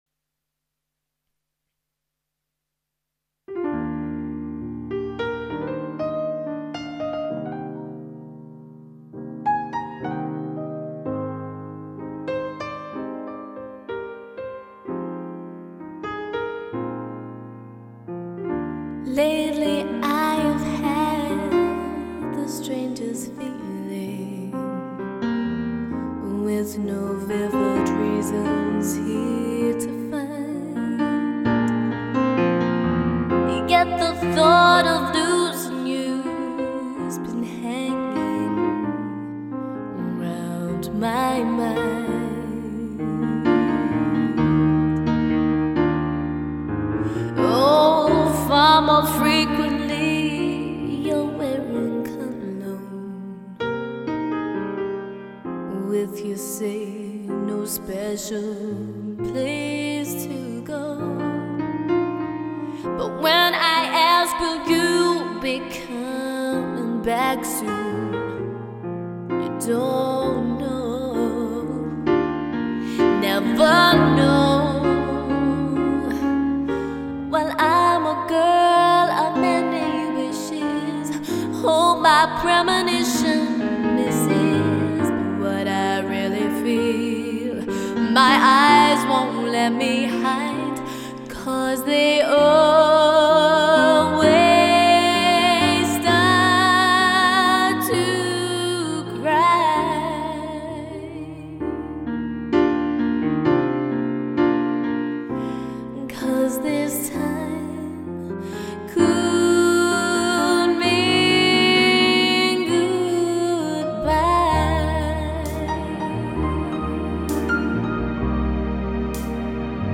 A top-class pianist with a distinctive soulful singing voice